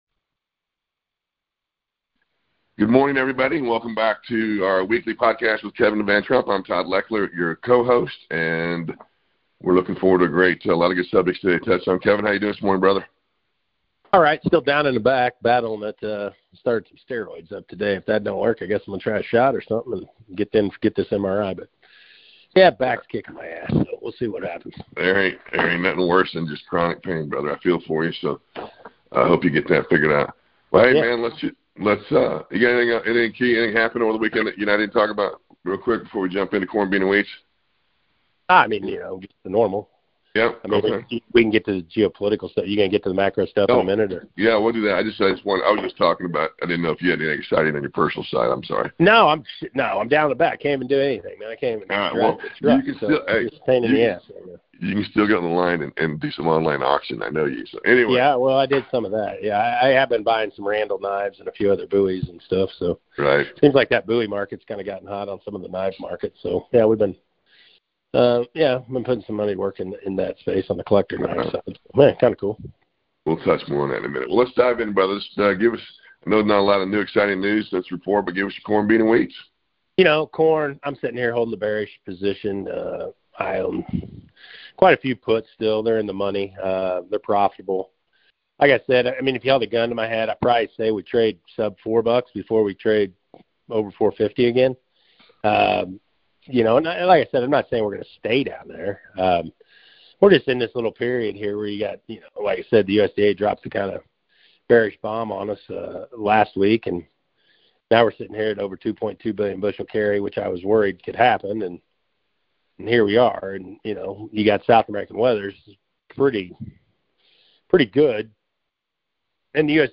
The pair also discusses the current macro and geopolitical wild-cards and how they are positioning their current investments, including stocks, metals, real estate, land, etc…